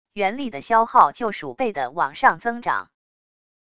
元力的消耗就数倍的往上增长wav音频生成系统WAV Audio Player